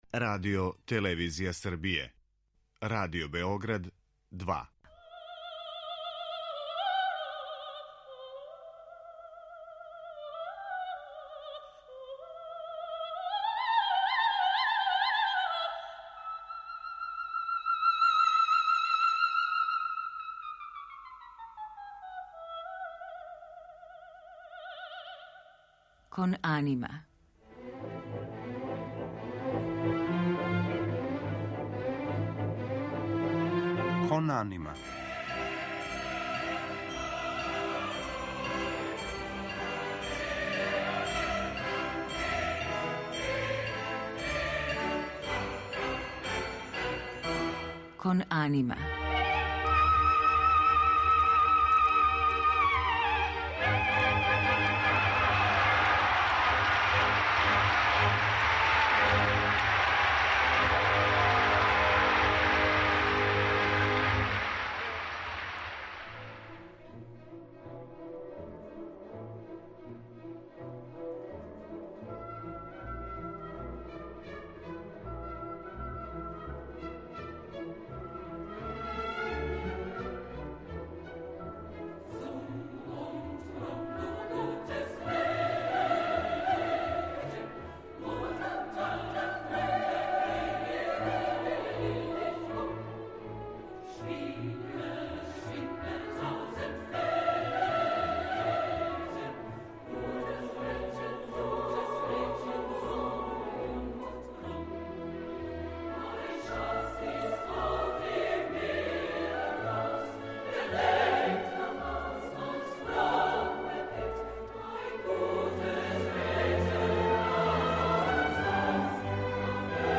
Кроз цитате из ове студије, и уз одабране одломке Вагнерових опера